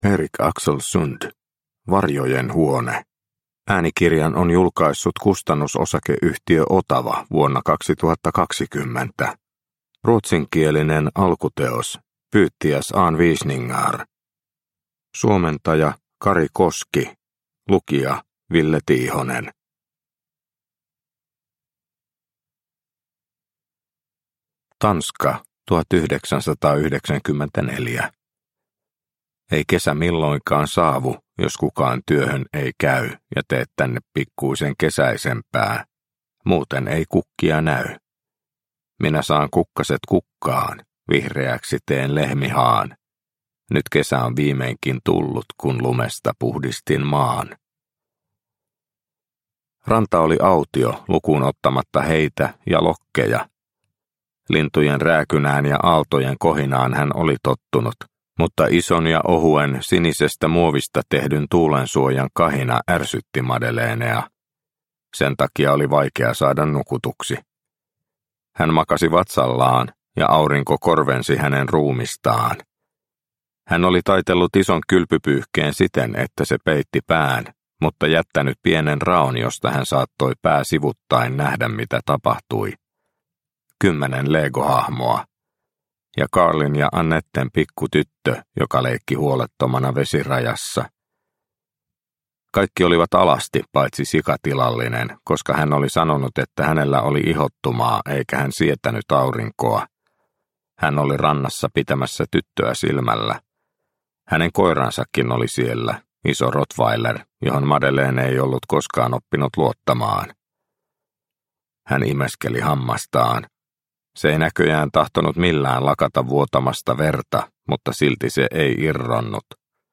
Varjojen huone – Ljudbok – Laddas ner